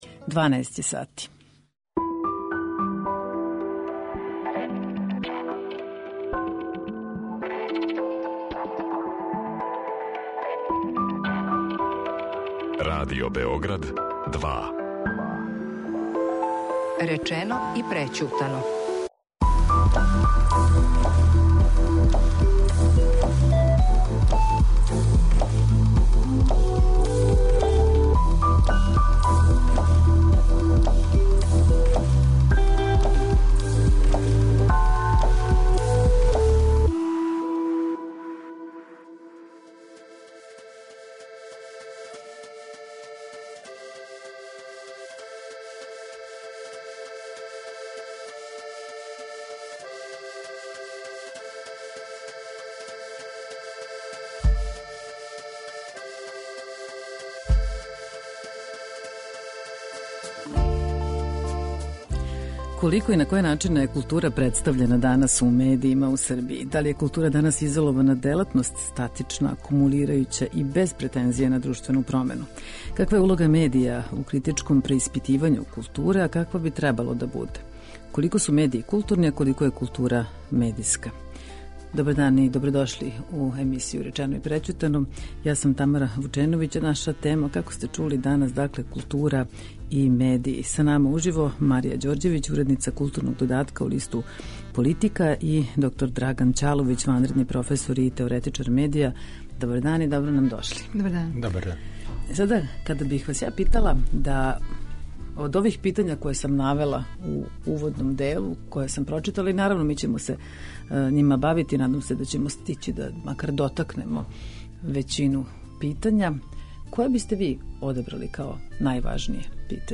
Речено и прећутано | Радио Београд 2 | РТС